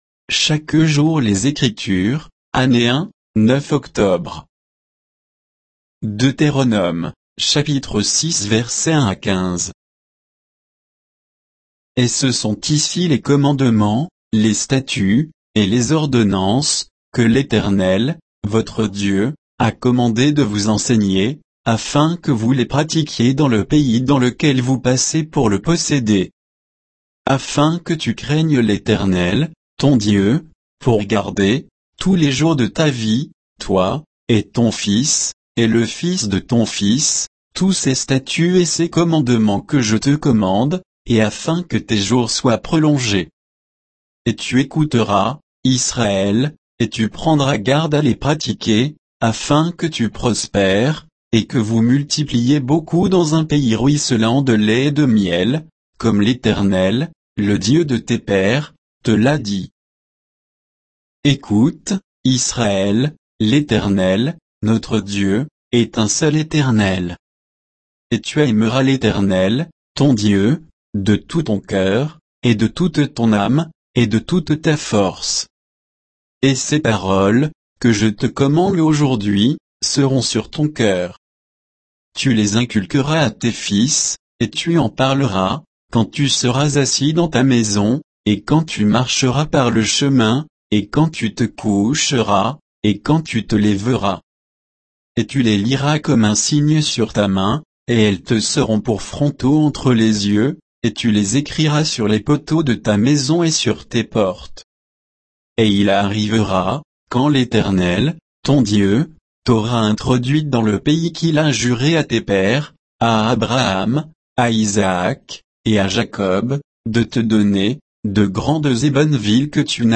Méditation quoditienne de Chaque jour les Écritures sur Deutéronome 6, 1 à 15